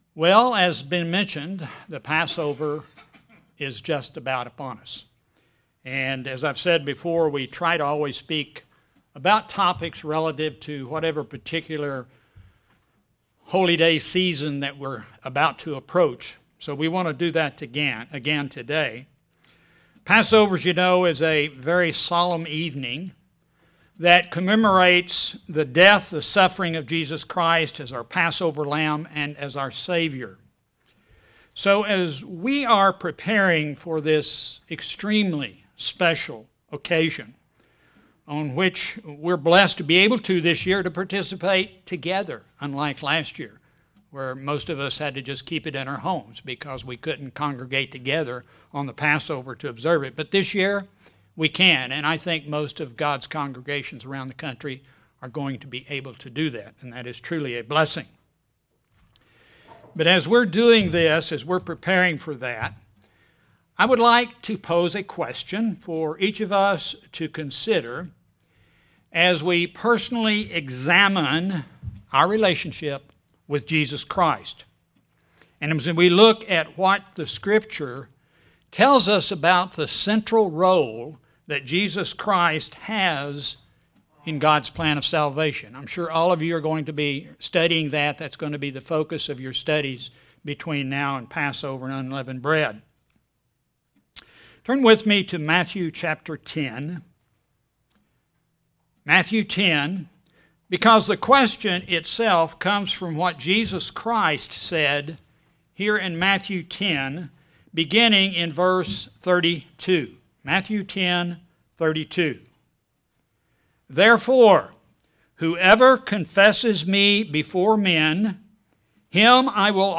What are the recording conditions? Given in Springfield, MO